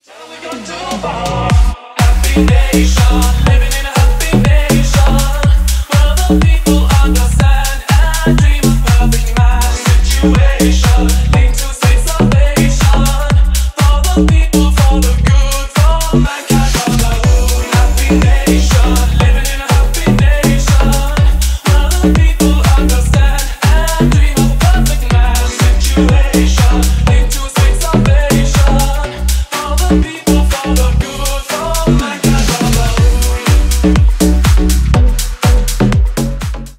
Ремикс
Танцевальные
клубные